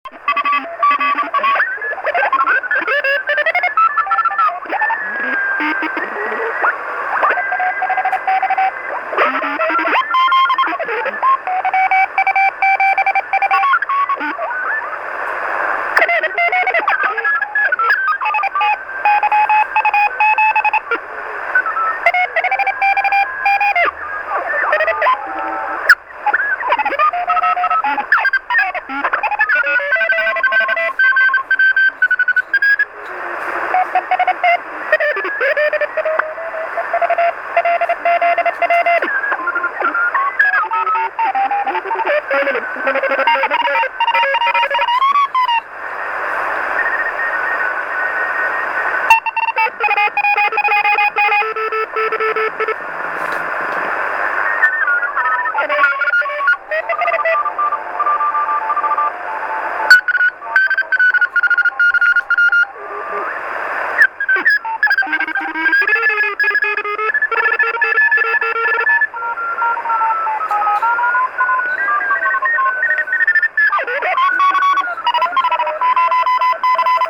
Послушал тест "CQ-WW-CW" на самоделке, только что, на 7 мГц.
Но, сегодня просто прошу послушать работу самоделки на приём в тесте.